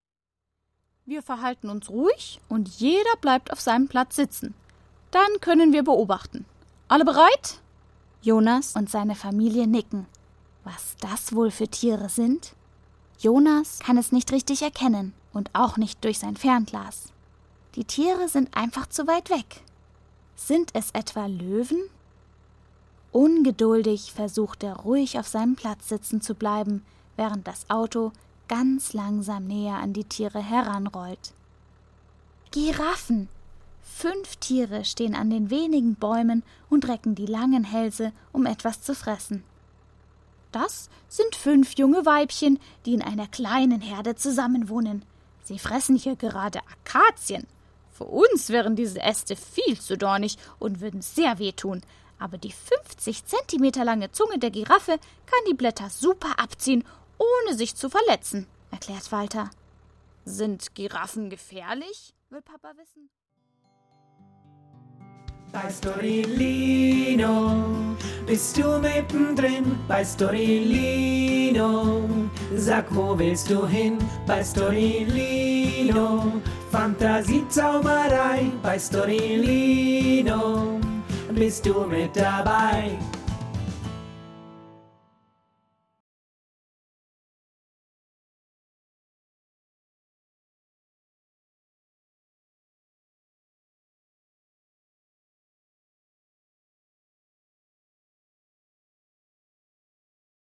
Ausschnitte aus der Hörgeschichte